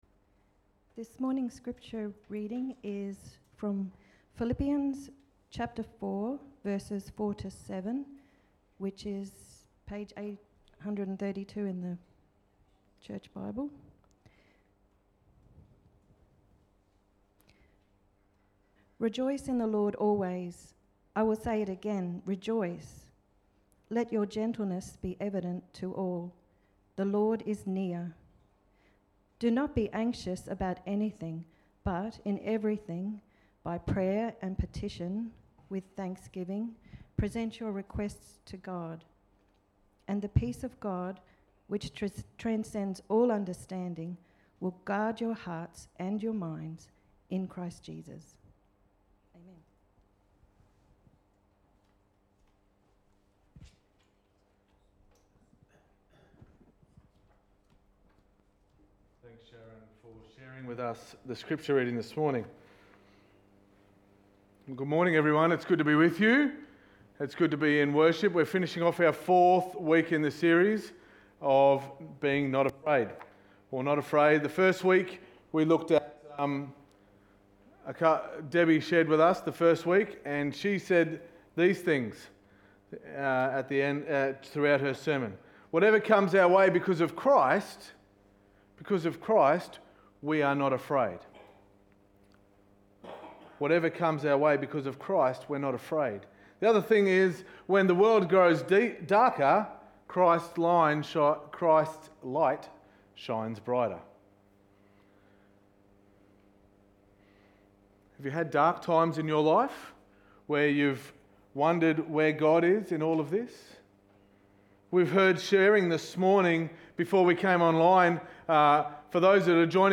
Sermon 25.10.2020